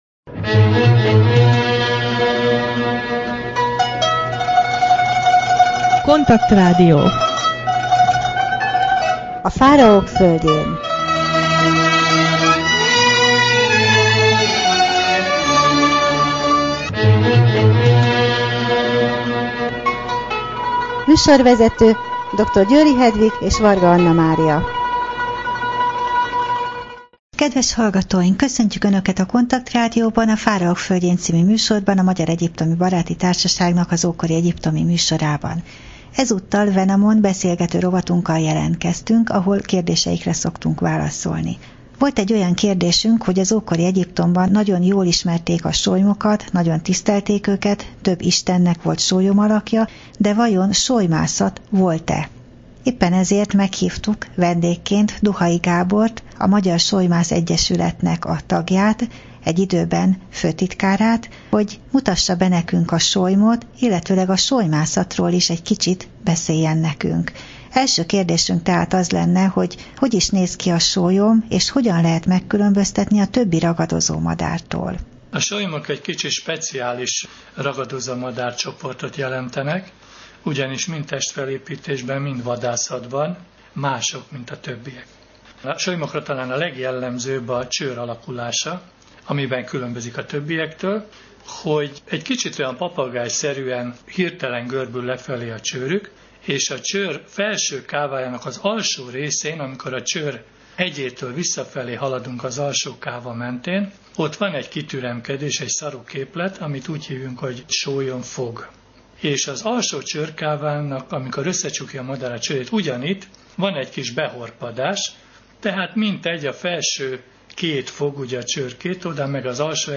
Wenamon beszélgető rovat: Sólymok és sólymászat